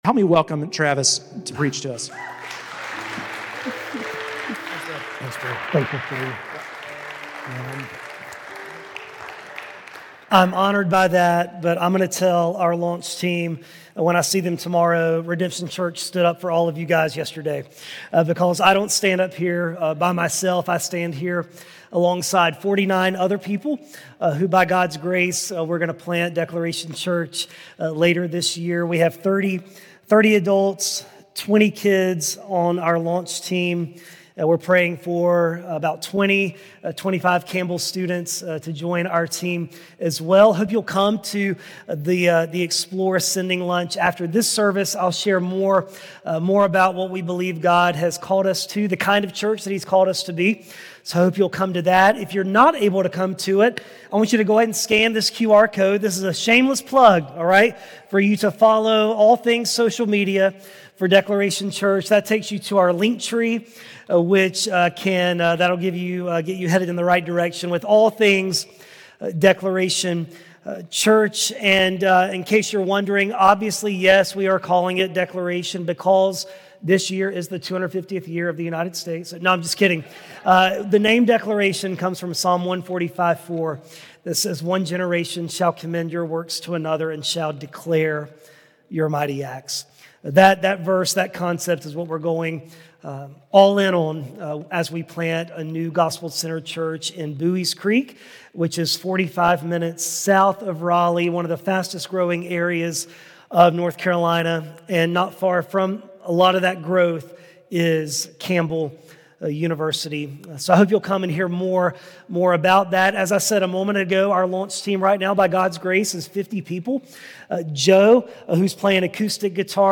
Today for Send Sunday we heard from a special guest speaker